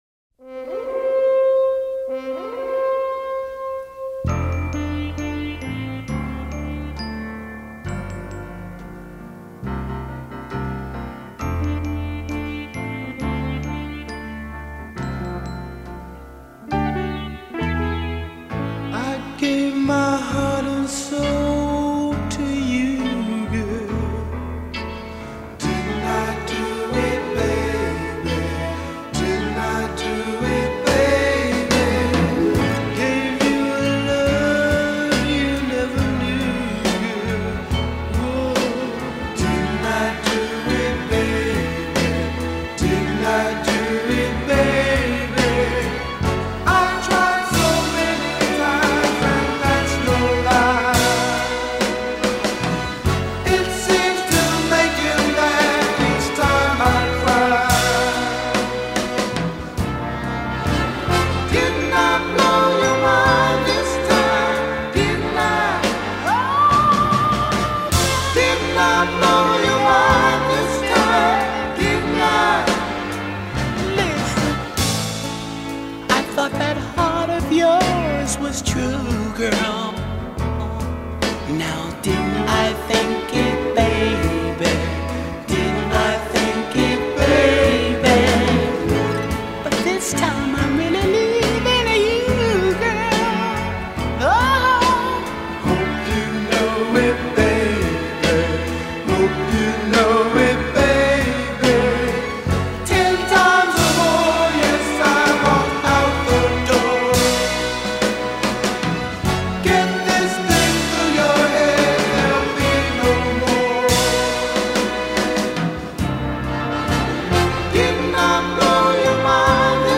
R&B/Soul vocal group